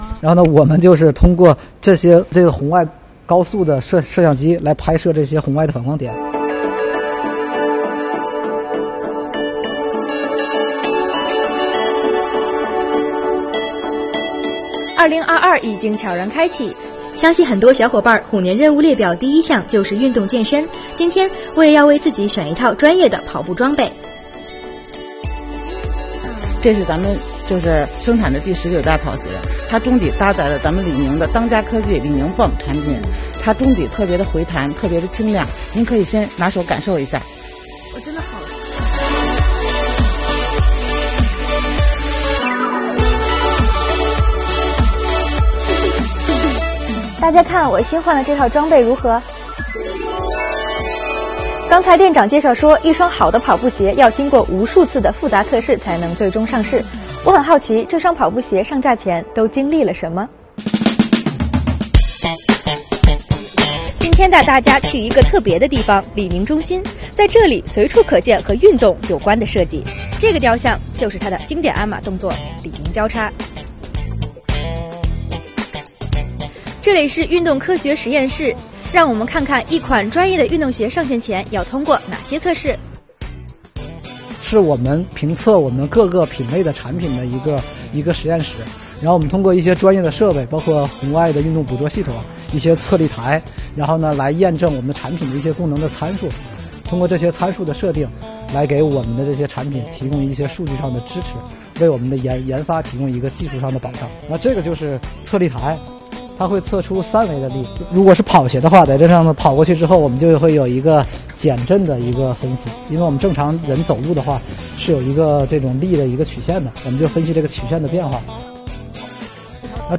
今天，本报记者带大家探访李宁运动科学实验室，揭秘一款专业的运动鞋上线前要经过哪些“考试”，看科技如何助“跑”。